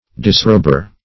Disrober \Dis*rob"er\, n. One who, or that which, disrobes.